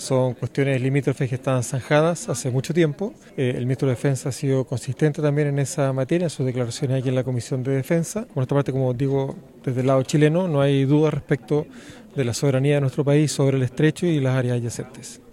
En la misma línea, el diputado Jaime Bassa (FA) afirmó que son determinaciones que se encuentran fijadas en tratados suscritos y que el ministro Barros había sido claro en ese punto.